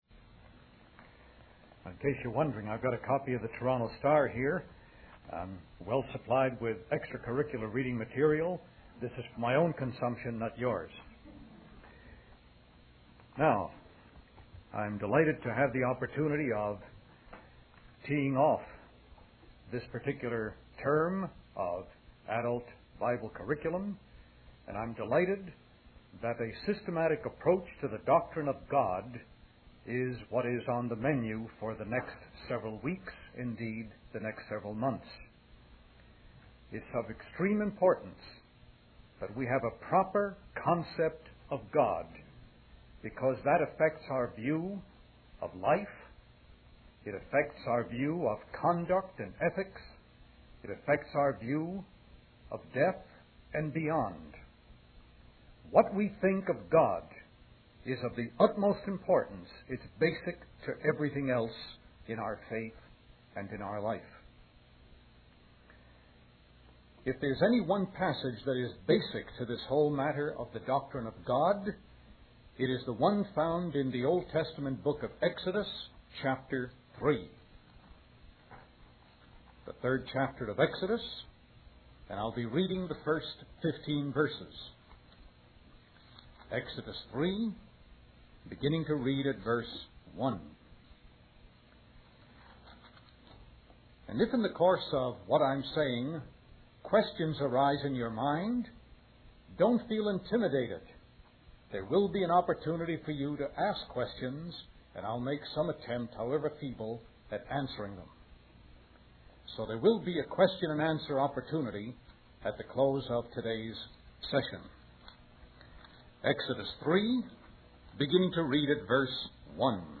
In this sermon, the speaker emphasizes that God is knowable and desires to make himself known to us. He distinguishes between natural revelation, where God reveals himself through the world he has created, and supernatural revelation, where God reveals himself through his word.